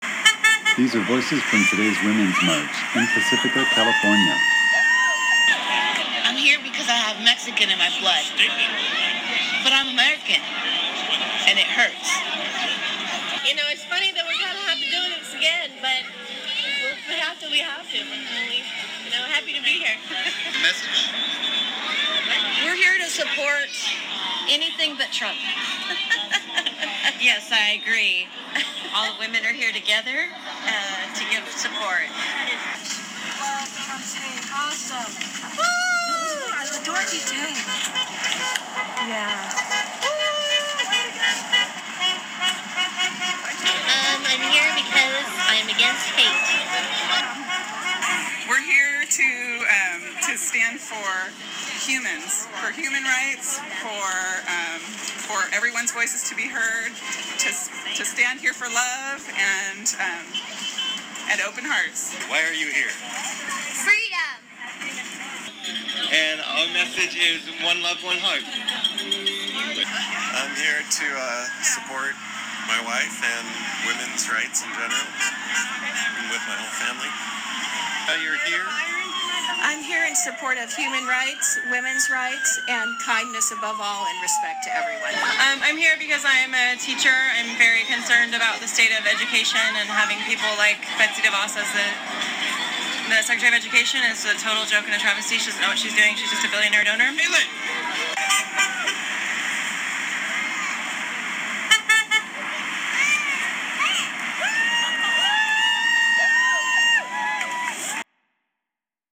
Pacifica-Womens-March2017.m4a